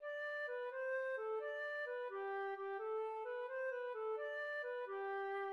Here are the melody for the first two lines.